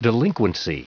Prononciation du mot delinquency en anglais (fichier audio)
Prononciation du mot : delinquency